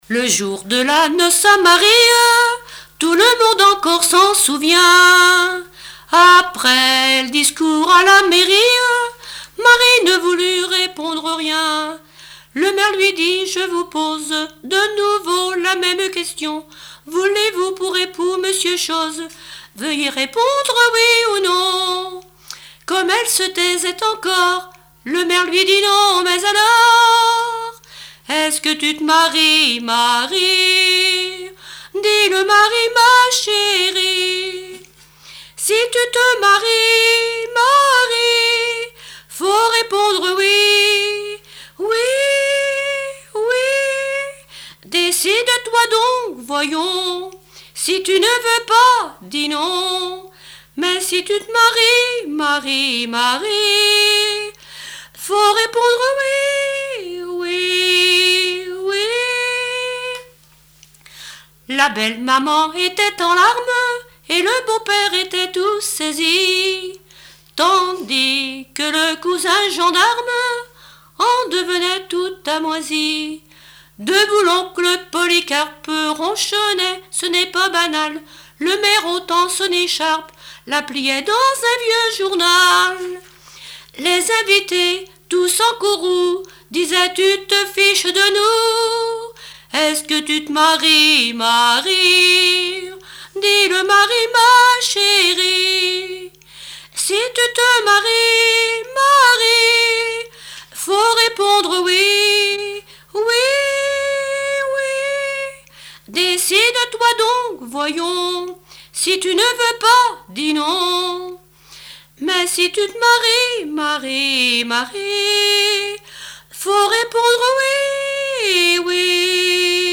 Mémoires et Patrimoines vivants - RaddO est une base de données d'archives iconographiques et sonores.
Chansons de variété et populaires
Pièce musicale inédite